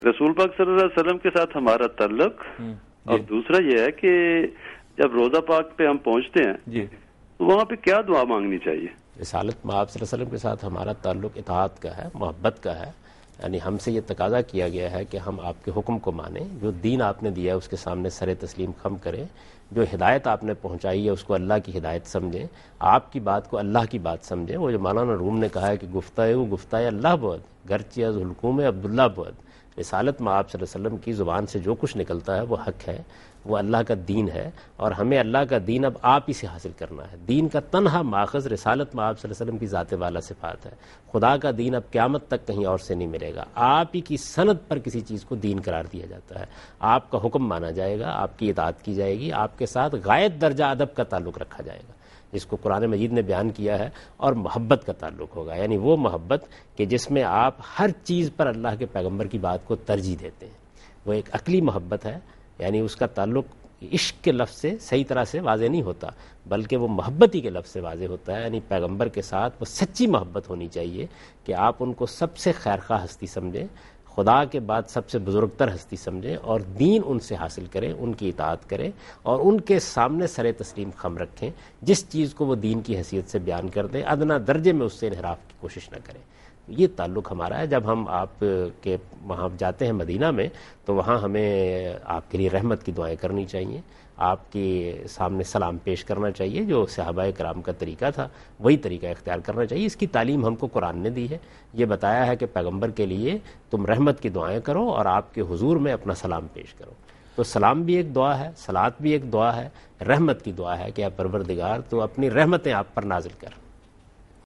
Category: TV Programs / Dunya News / Deen-o-Daanish /